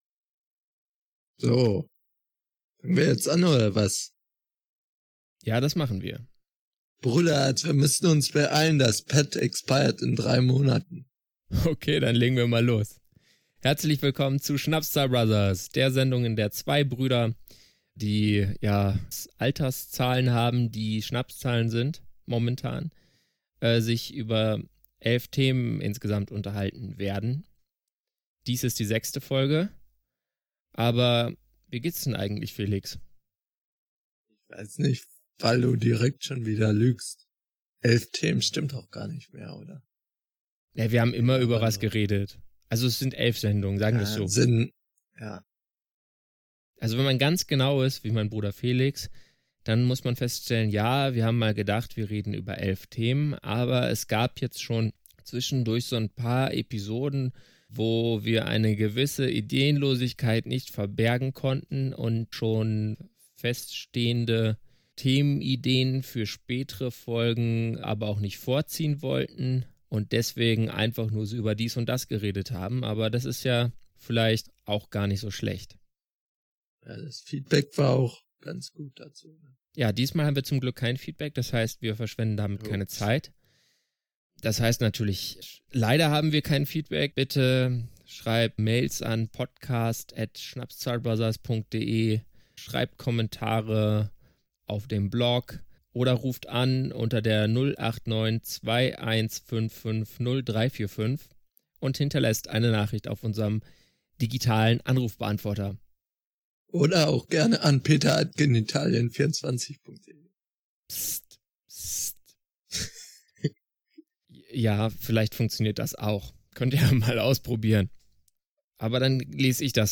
Wenn unsere linksgrünversifften, moralinsauren Lieblingspodcastbrüder dennoch akzeptabel klingen, so ist dies nur den Wundern der Technik zu verdanken – bzw. dem Geld, was für die Produktionssoftware ausgegeben wurde und der Zeit, die investiert wurde.